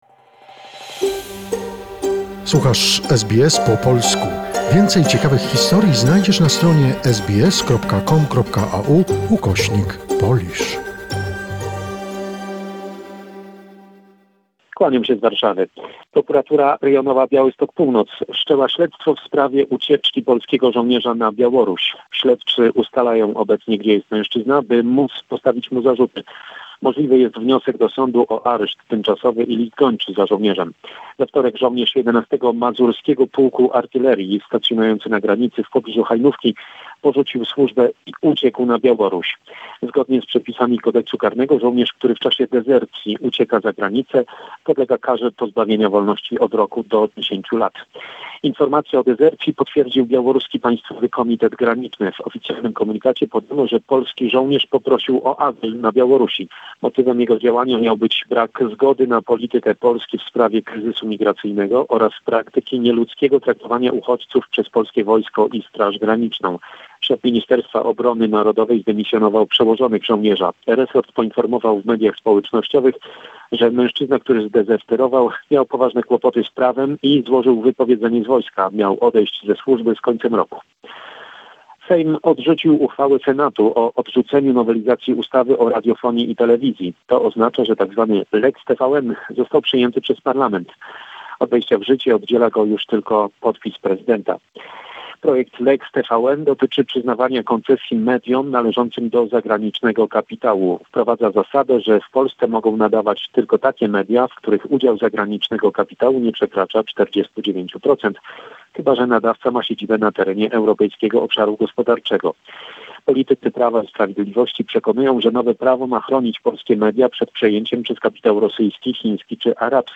Summary of the important events in Poland. Report